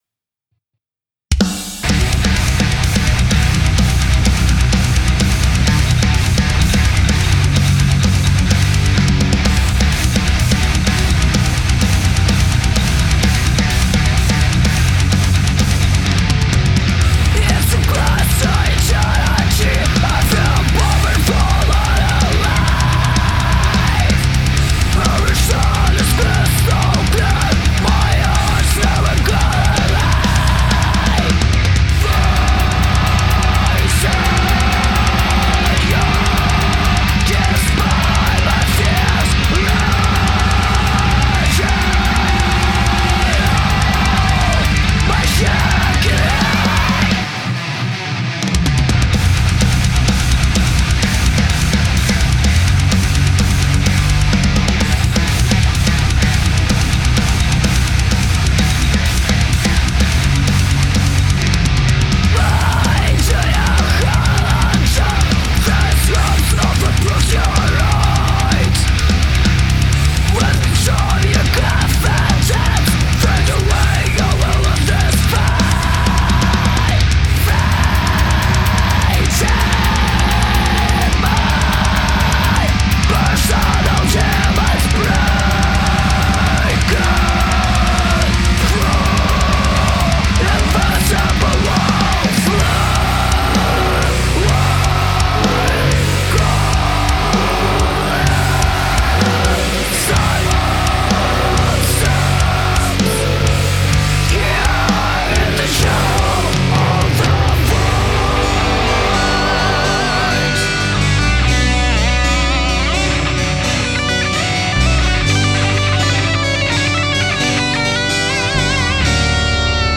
Стиль: Melodic Death Metal | Female Vocal